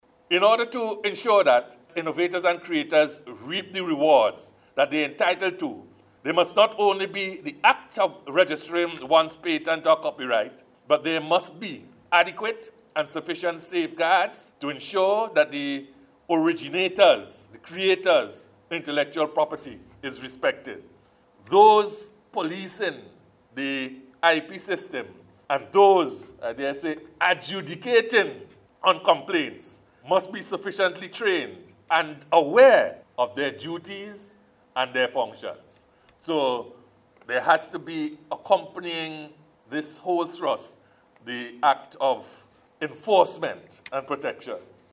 Justice Williams was the Keynote Speaker at a Conference and Exhibition hosted by the Association of Music Professionals (AMP) on Tuesday to coincide with World Intellectual Property Day.